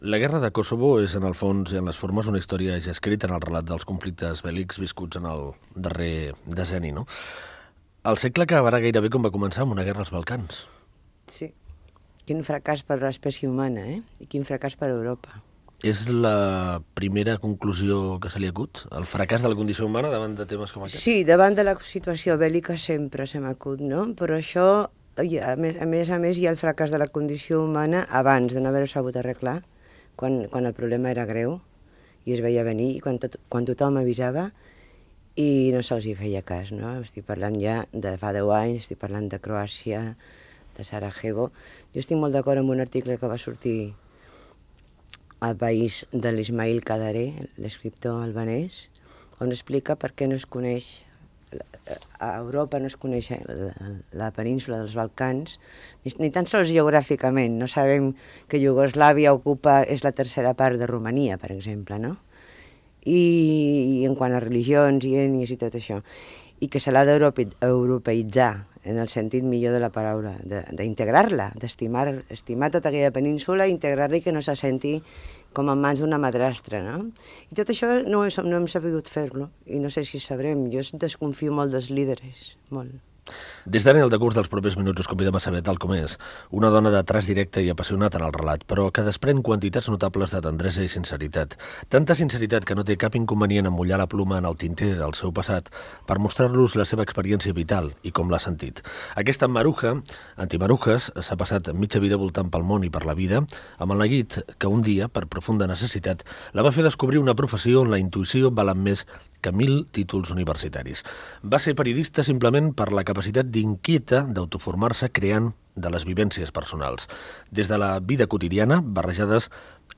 Fragment d'una entrevista a la periodista i escriptora Maruja Torres.